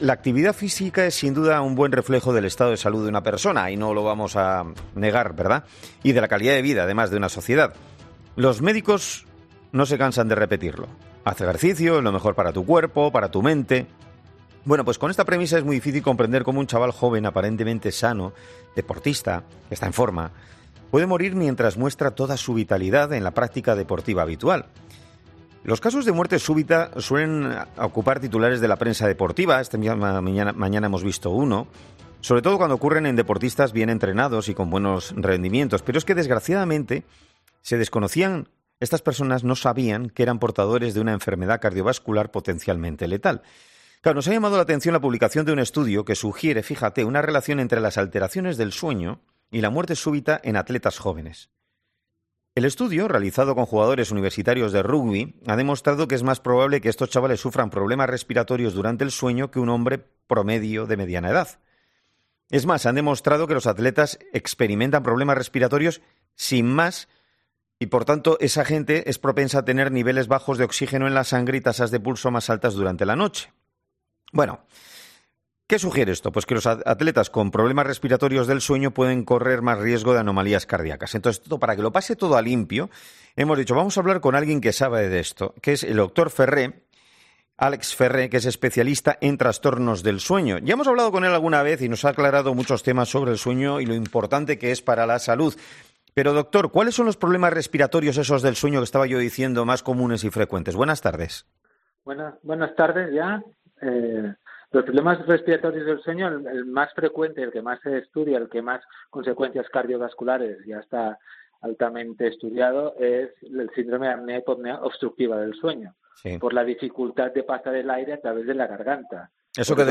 especialista en trastornos del sueño